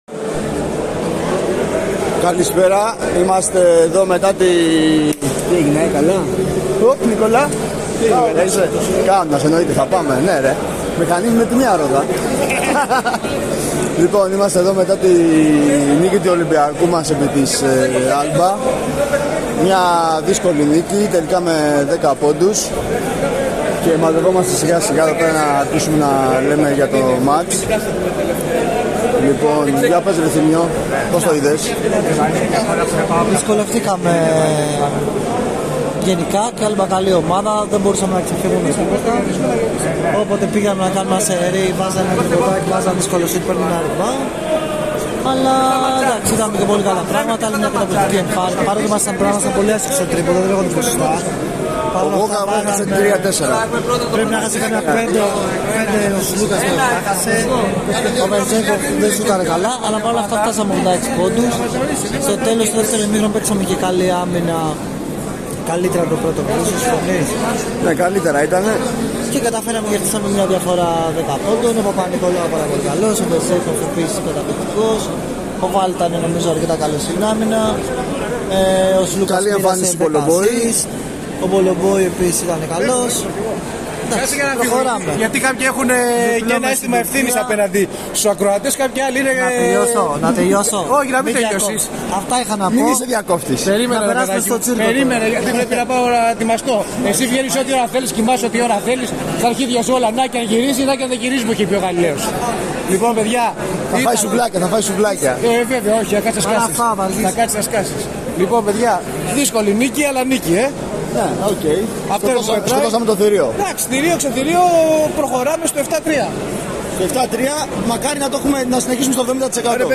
Ακούμε με προσοχή το κλίμα μετά τη νίκη του Ολυμπιακού επί της Αλμπα στο ΣΕΦ.